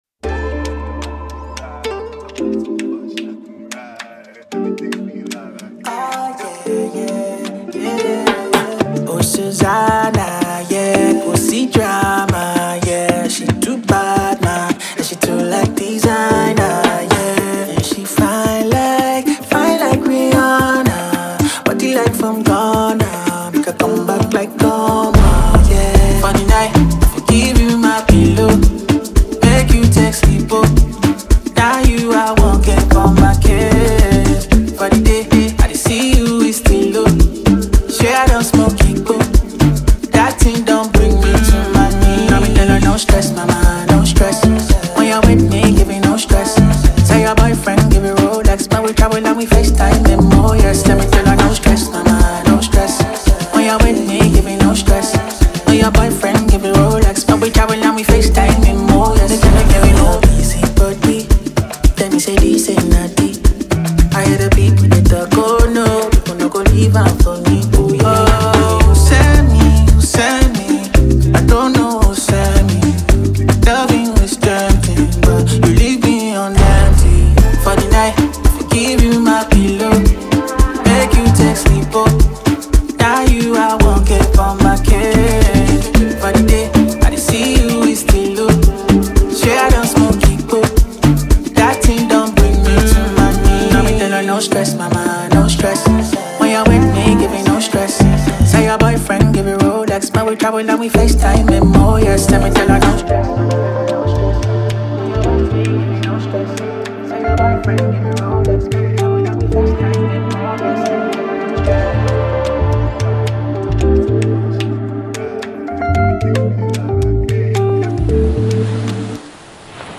soulful and irresistible tune